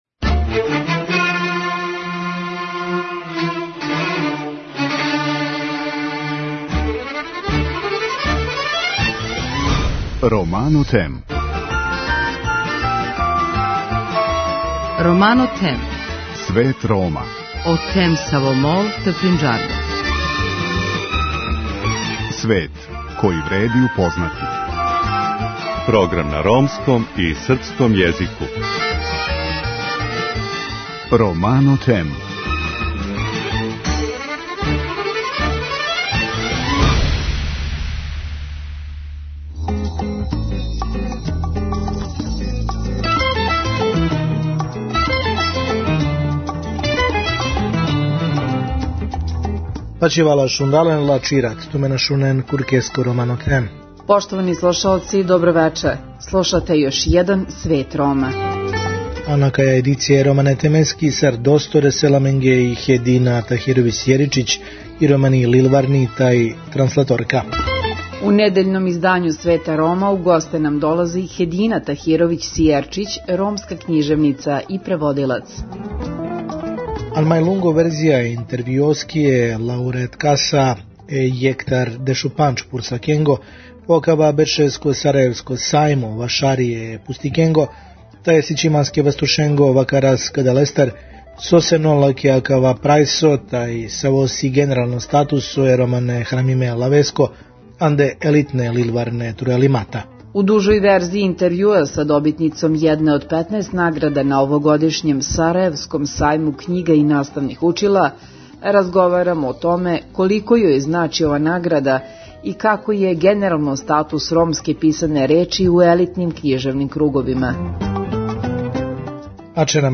У вечерашњој емисији слушамо дужу верзију интервјуа